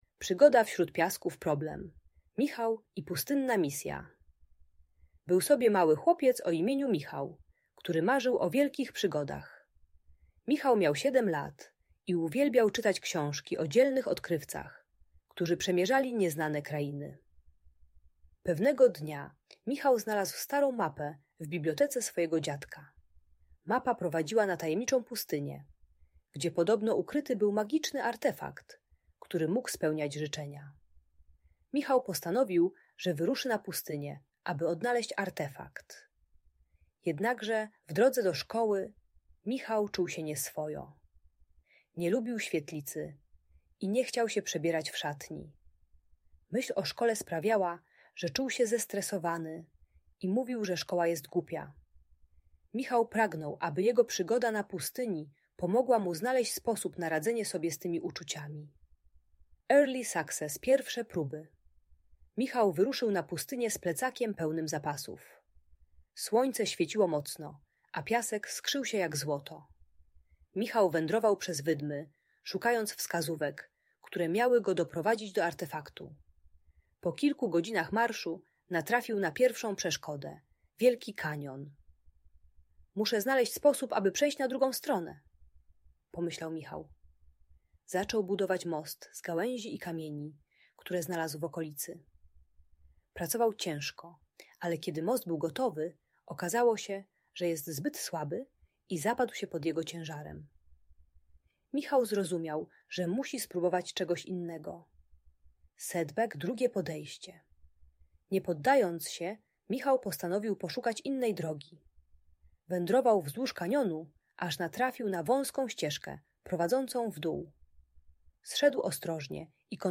Przygoda Michała wśród piasków - Opowieść o odwadze - Audiobajka